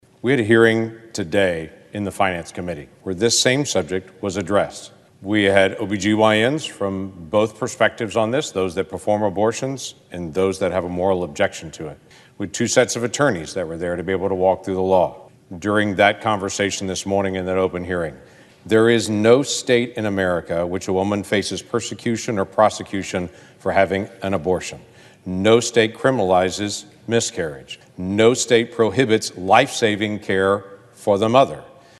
Oklahoma Senator James Lankford took to the floor on Tuesday and spoke out in opposition of the democrats attempting to force an abortion vote through the Senate.
Lankford on Abortion Clip 1.mp3